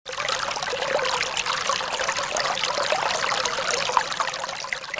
河流 | 健康成长
river.mp3